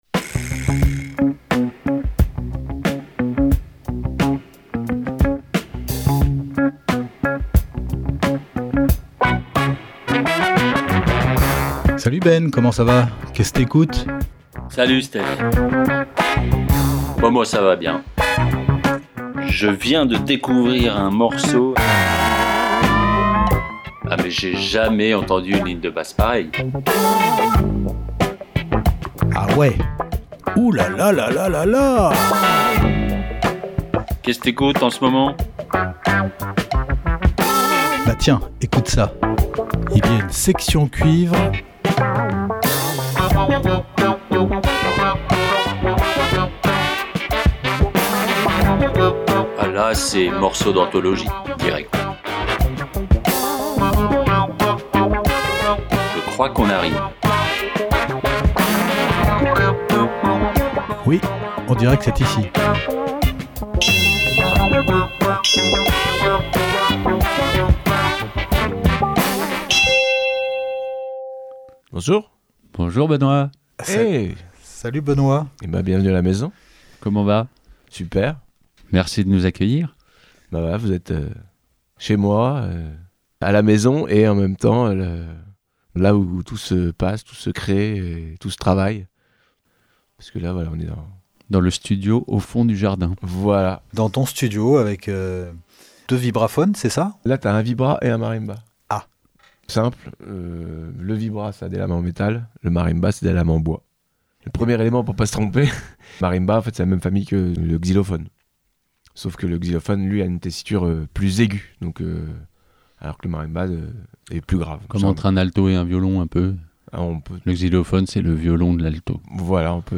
Découvertes musicales Classique & jazz